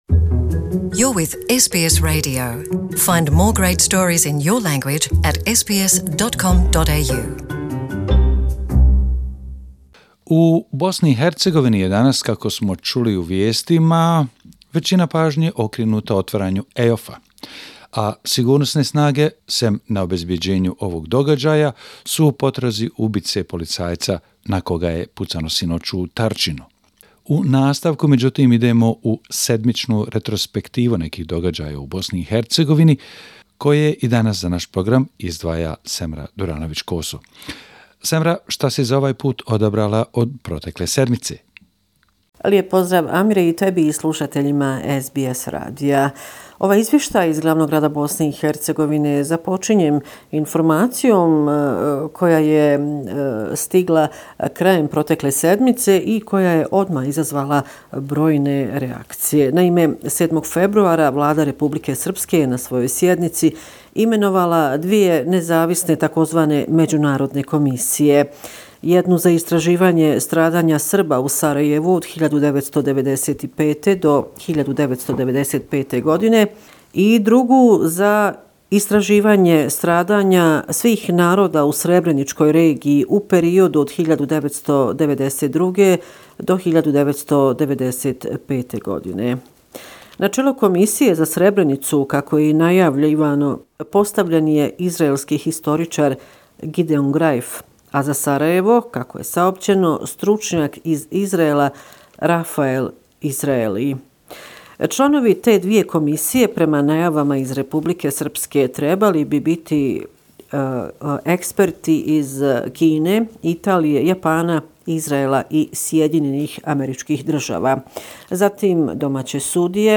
Bosnia and Herzegovina, weekly report, February 10, 2019